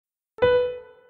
На этой странице собраны звуки нот в разных тембрах: от классического фортепиано до электронного терменвокса.
Нота Си в музыке